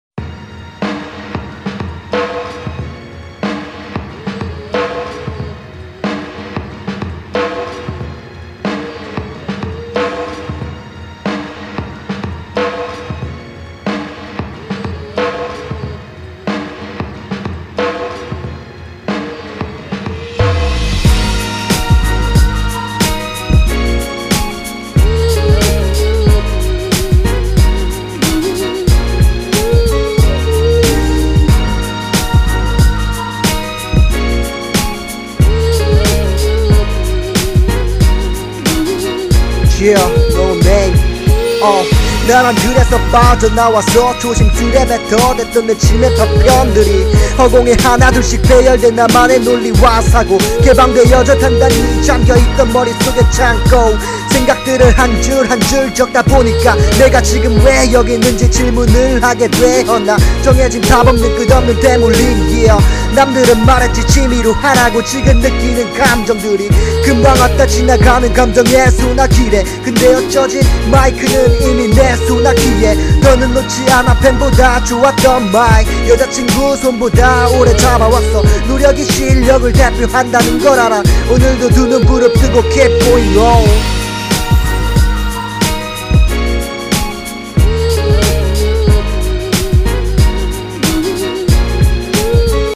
자작랩
3 Thumb Up 미디어 듣기 집에서 혼자 녹음해봤습니다..
발성은 어느정도 연습하신것 같은데 발음이 아쉽네요^^ 열심히 하는모습 보기좋습니다 ㅎㅎ « Prev List Next » Modify Delete